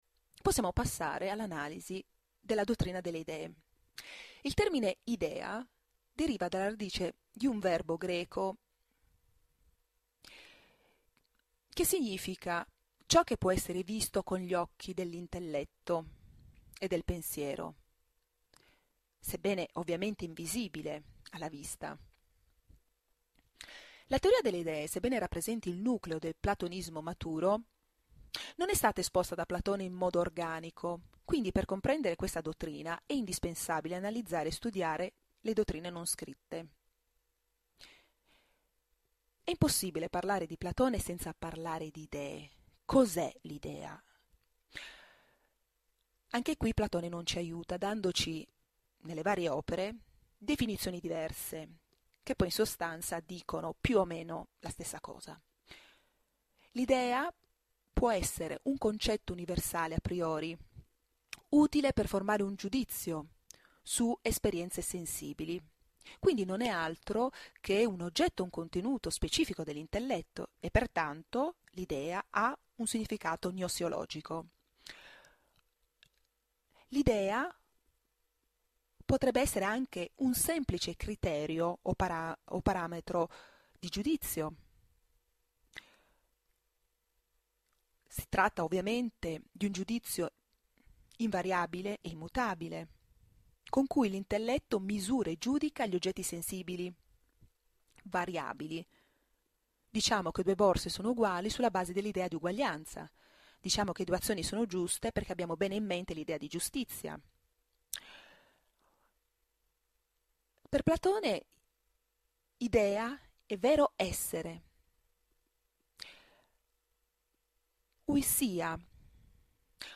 Anticipazione lezioni audio Platone.mp3